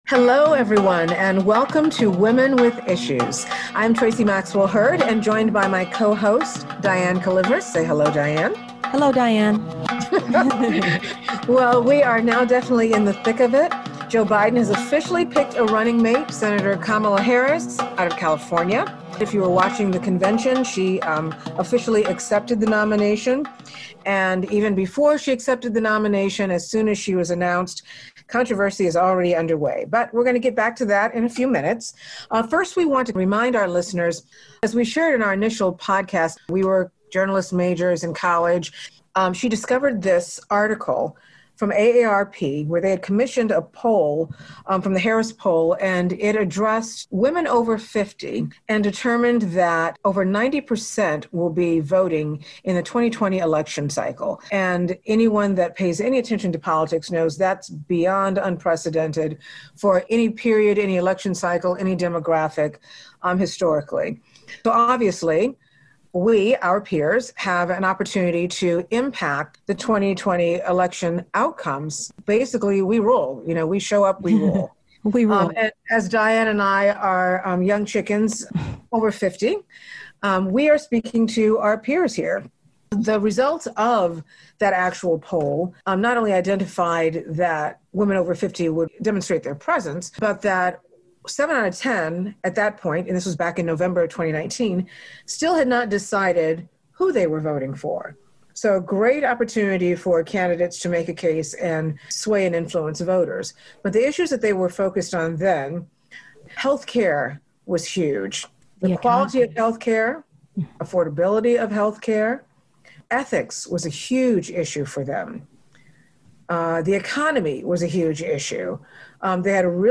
Hosts discuss the shifted priorities of women over 50, mail-in voting and the Kamala Harris effect.